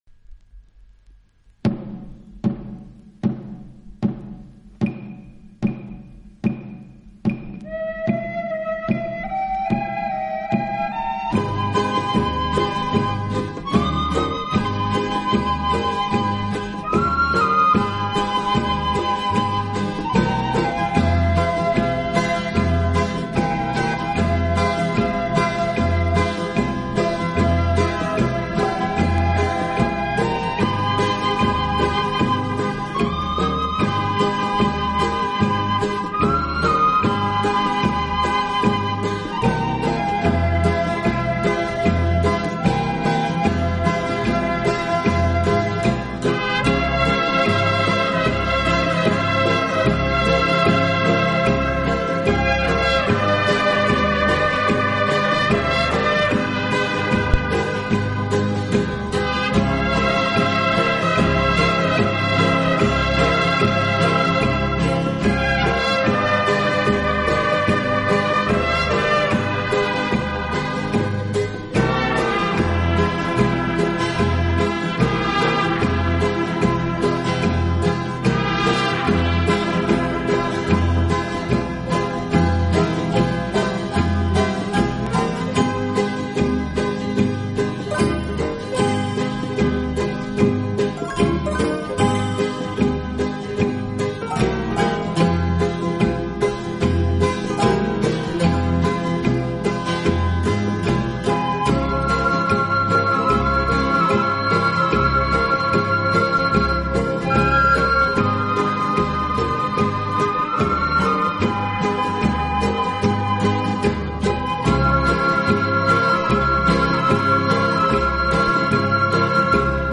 【轻音乐】
美洲音乐而著称。演奏轻柔优美，特別是打击乐器的演奏，具有拉美音乐独特的韵味。